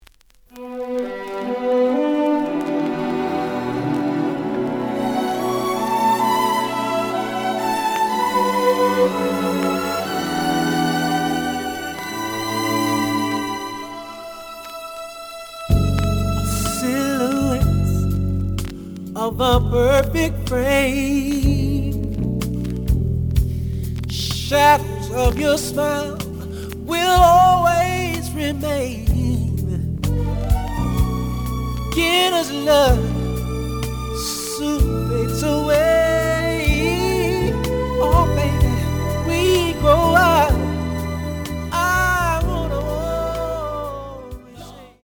The audio sample is recorded from the actual item.
●Genre: Soul, 80's / 90's Soul
Slight noise on beginning of A side, but almost plays good.)